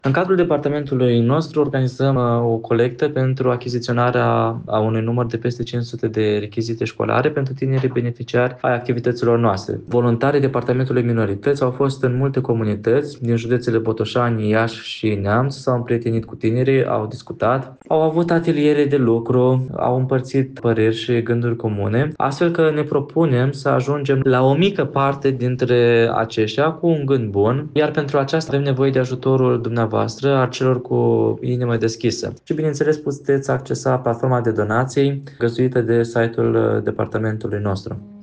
Într-o declarație pentru Radio Iași